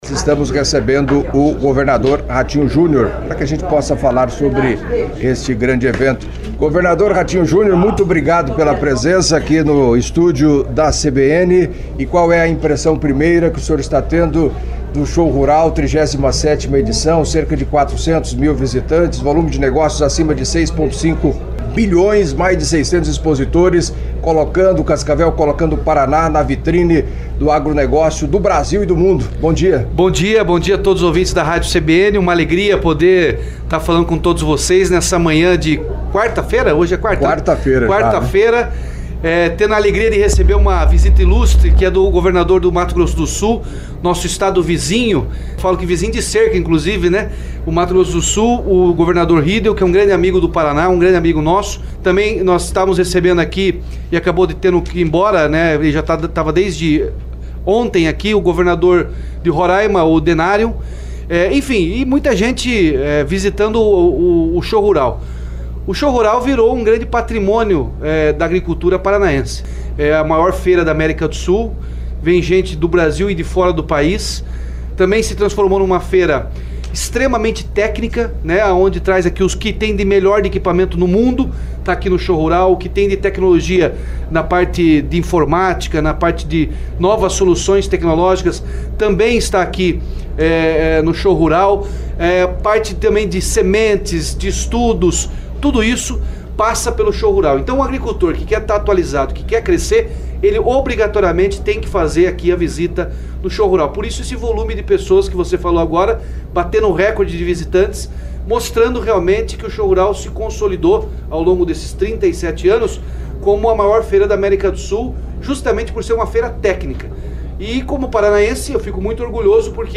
Entrevista à CBN Cascavel nesta quarta-feira (12), no estúdio montado no Parque Tecnológico Coopavel, no estande do BRDE, o governador Ratinho Júnior destacou a importância do Show Rural; falou do crescimento econômico do Paraná nos últimos anos e as boas perspectivas para os próximos; comentou sobre a possibilidade de ser candidato à Presidência da República; sucessão no estado e ao respnoder dúvidas de ouvintes reafirmou que Paranhos, ex-prefeito de Cascavel, estará presente na equipe de governo, a partir de março, acompanhe